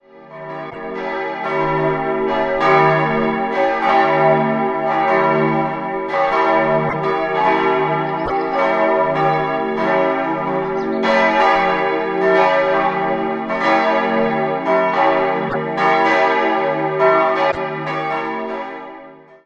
3-stimmiges Kleine-Terz-Geläute: cis'-e'-g'
Die Gussstahlglocken wurden 1924 vom Bochumer Verein gegossen.